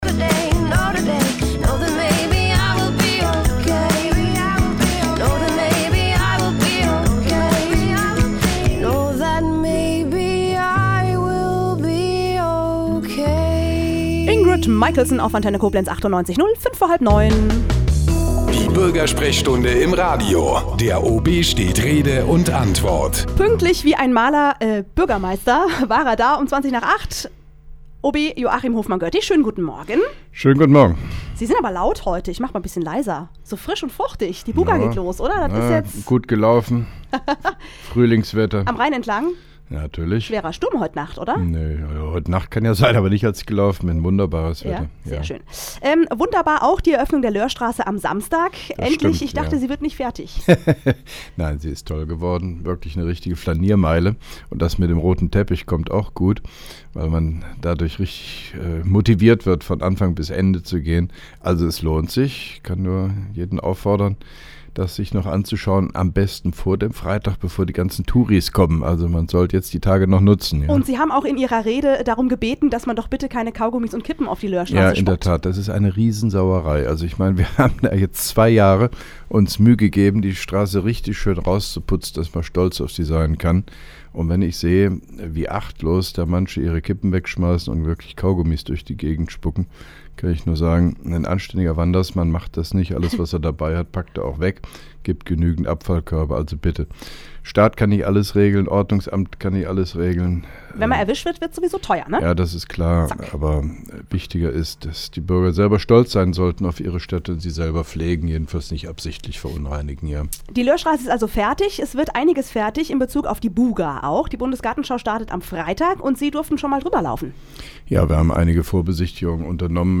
(1) Koblenzer Radio-Bürgersprechstunde mit OB Hofmann-Göttig 12.04.2011